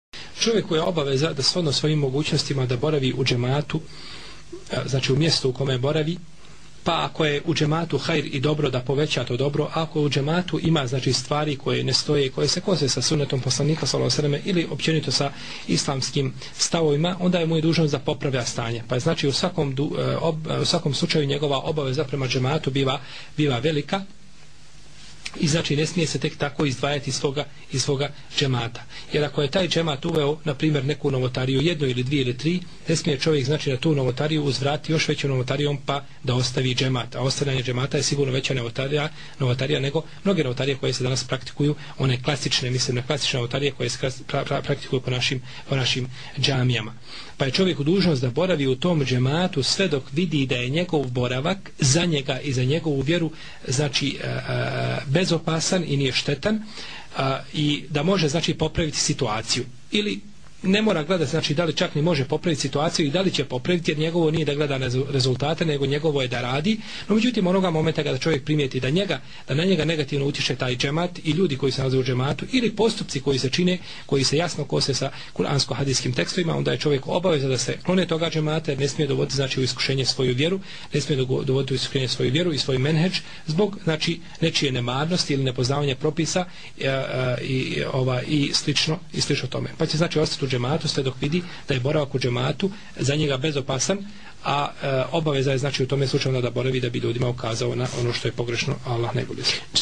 Odgovor počinje na 26,15 min u predavanju na linku ispod: